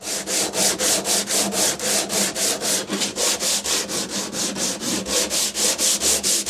Sanding | Sneak On The Lot